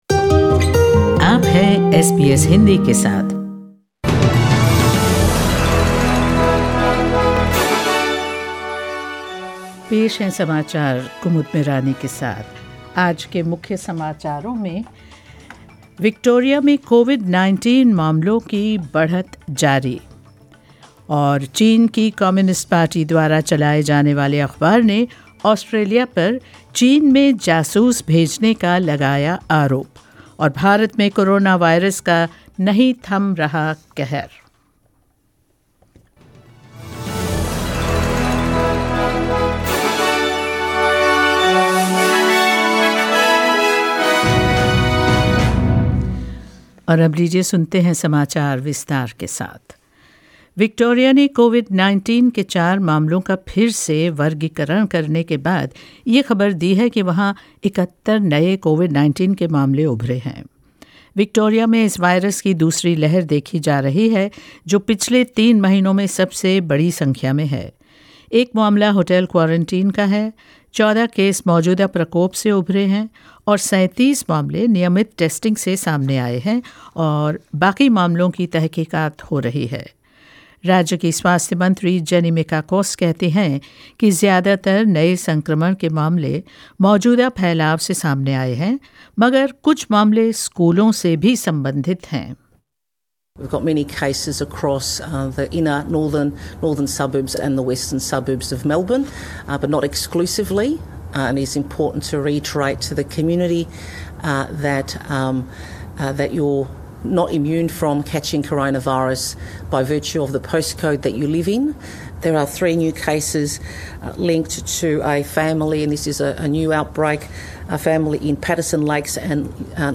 News in Hindi 29 June 2020